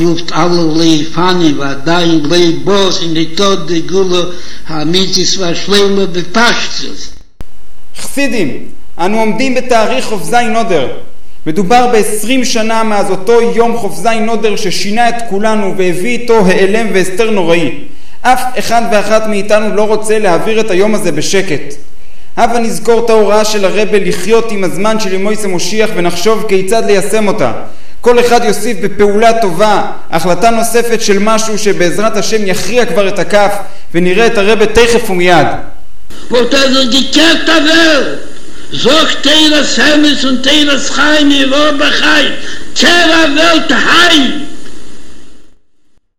רבבות חסידי חב"ד באה"ק הופתעו לקבל הודעת חיזוק טלפונית
לאחר שמיעת קולו של הרבי, התובע: "ועדיין לא בא! ס'איז ניטא דער גאולה האמיתית והשלימה בפשטות"!, הופנתה הקריאה להתעורר לרגל המצב המבהיל של 20 שנה ועדיין לא נושענו, להוסיף בעוד מעשה אחרון שיכריע את הכף  להאזנה